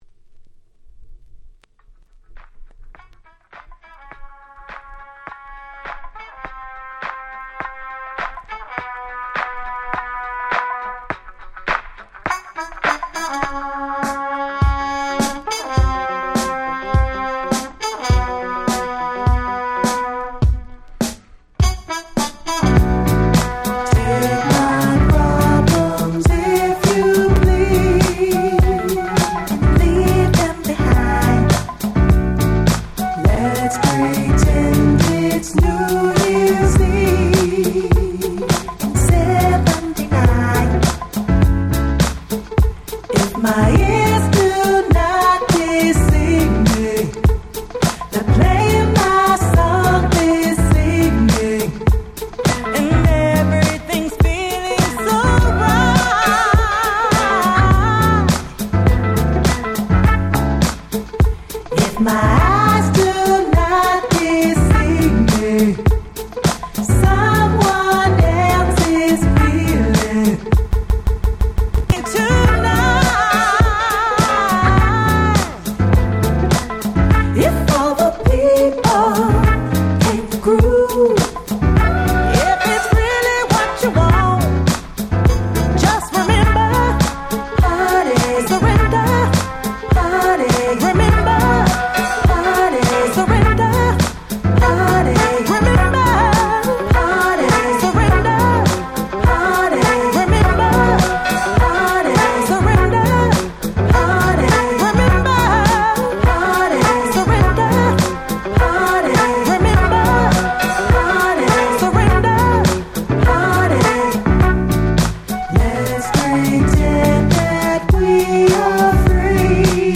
10' Super Nice Neo Soul / R&B !!